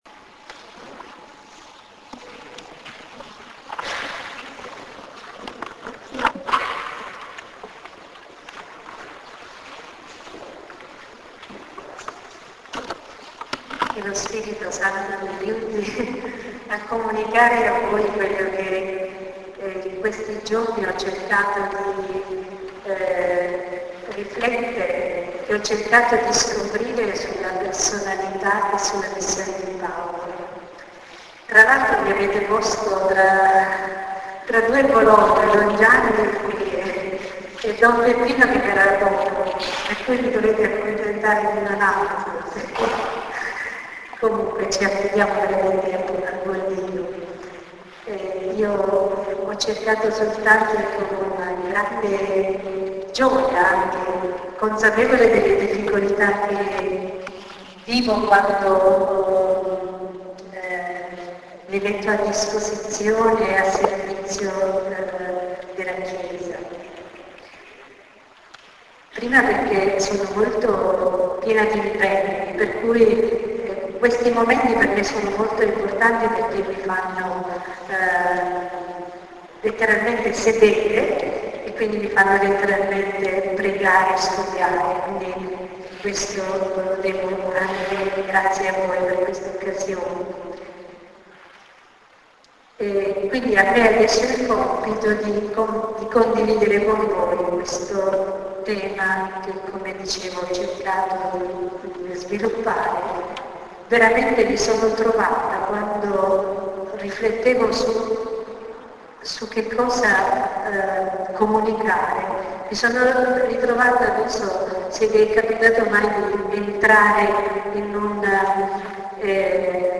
Rinnoviamo l'invito, esteso a tutti, giovani, famiglie per i prossimi 3 marted� di Quaresima (17,24,31) alle ore 20.00 a San Marcello. Meditazione